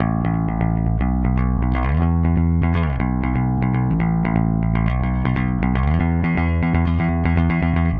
Power Pop Punk Bass 01a.wav